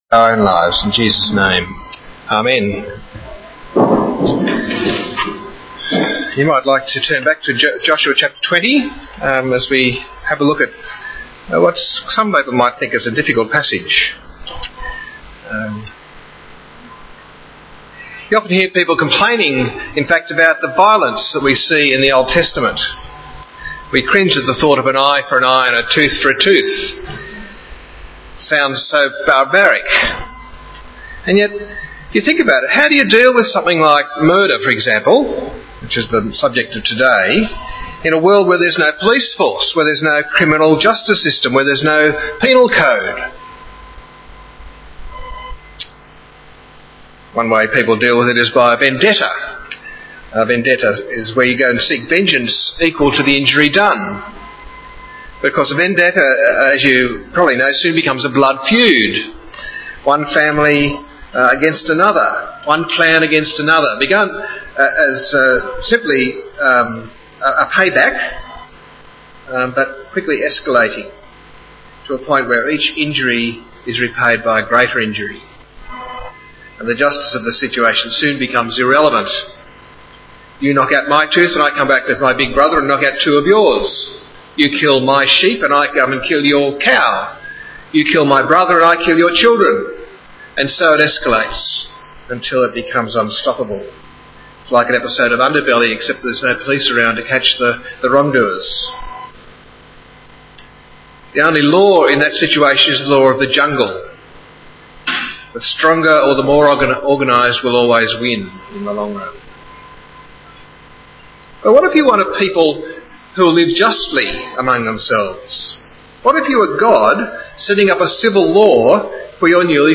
Sermons, etc.